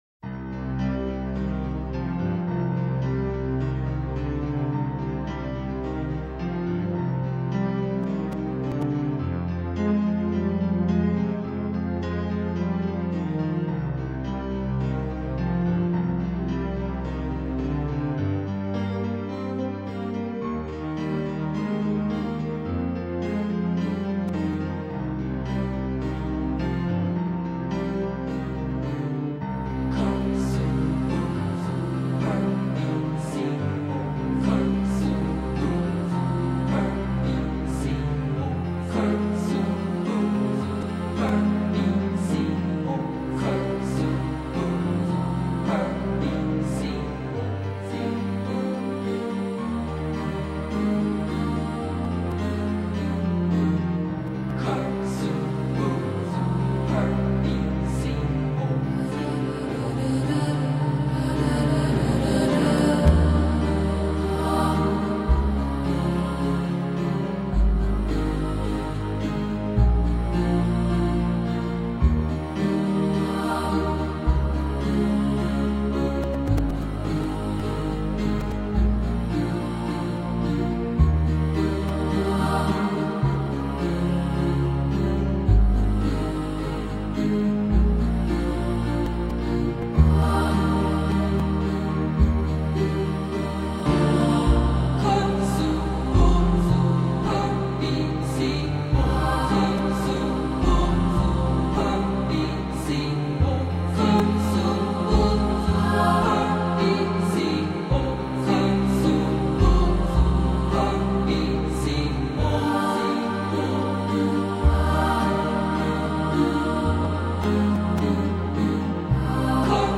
专辑中另一特色为赞美诗式宗教曲风的应用。
在宁静有致的钢琴声中，一波波人声袭来， 彷佛平静的水面，泛起一波波涟漪，袭袭的微风，吹拂过一望无际的水面。
演奏曲